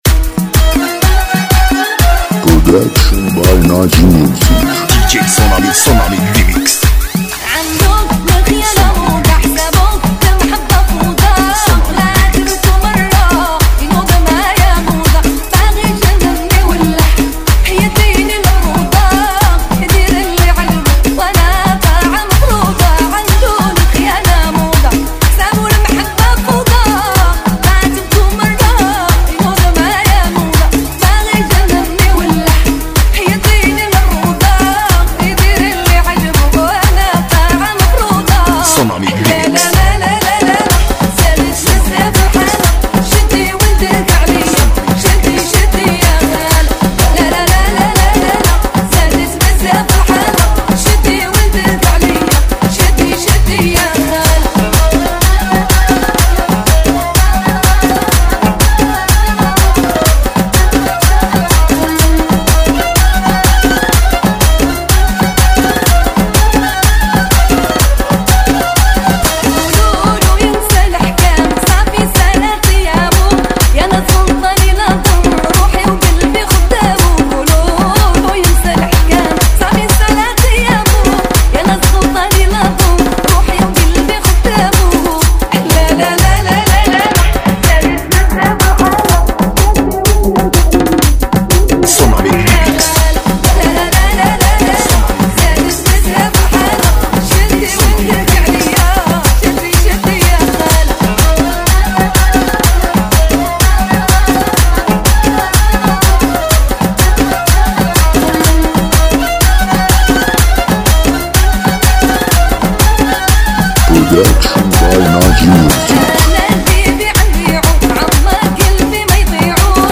ریمیکس شاد عربی مخصوص پارتی و دنس
ریمیکس عربی